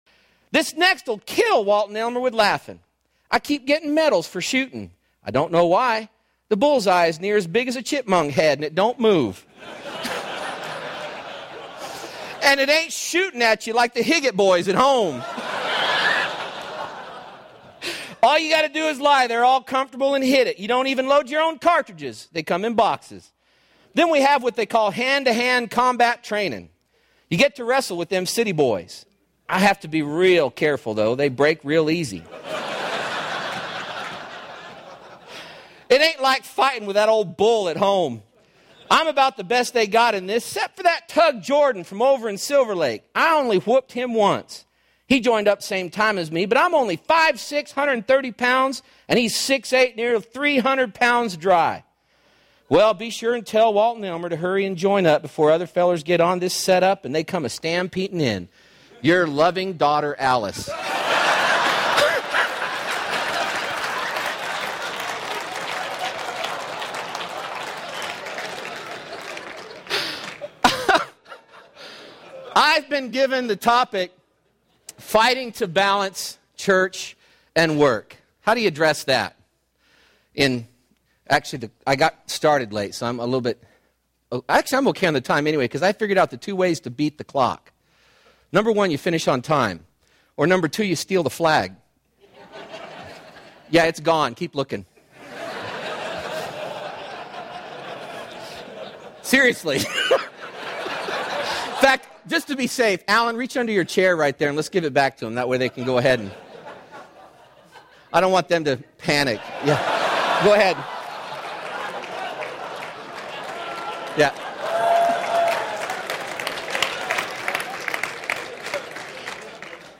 2010 Home » Sermons » Session 8 Share Facebook Twitter LinkedIn Email Topics